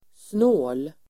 Uttal: [snå:l]